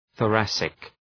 Προφορά
{ɵɔ:’ræsık}
thoracic.mp3